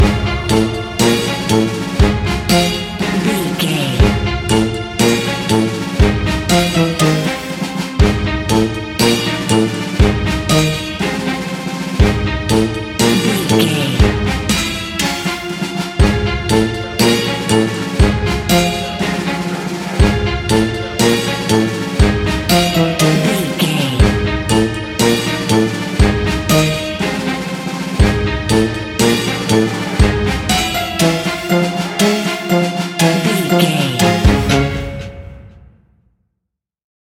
Aeolian/Minor
C#
Slow
scary
ominous
dark
eerie
bouncy
percussion
brass
synthesiser
strings
spooky
horror music